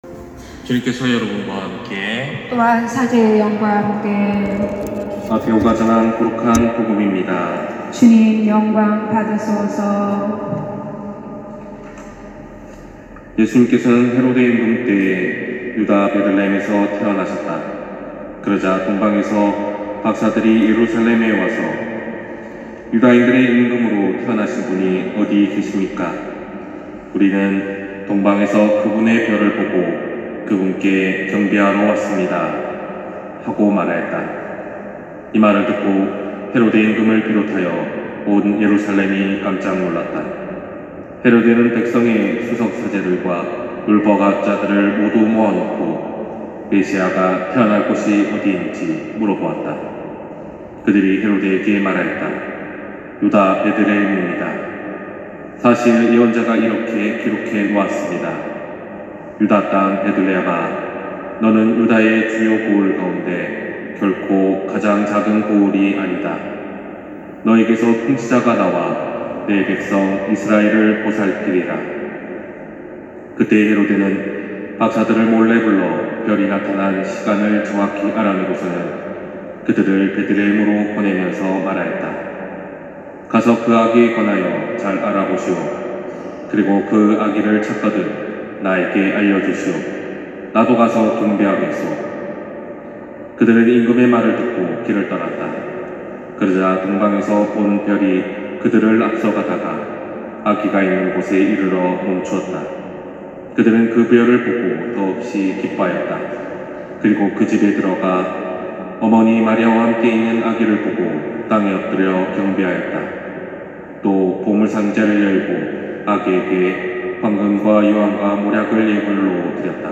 250104 신부님 강론말씀